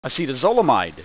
Pronunciation
(a set a ZOLE a mide)